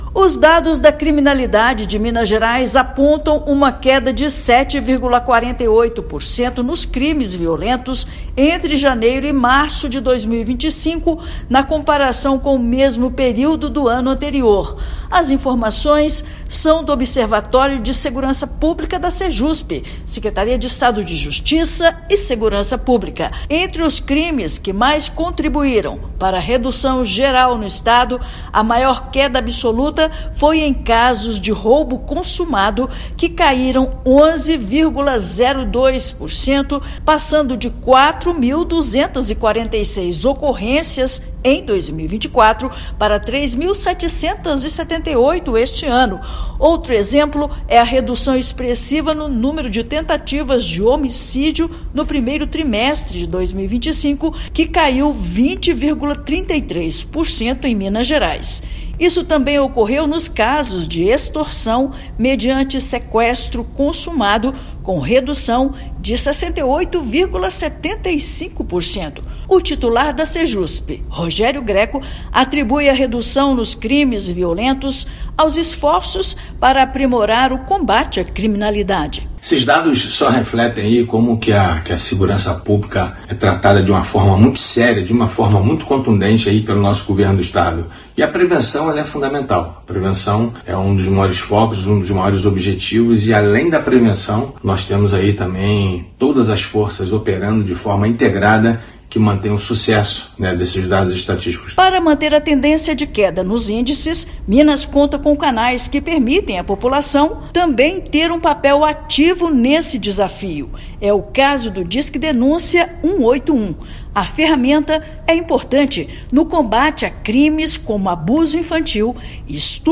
Roubo consumado caiu 11,02% no estado; em Belo Horizonte, a redução geral verificada no mesmo período foi de 1,65%. Ouça matéria de rádio.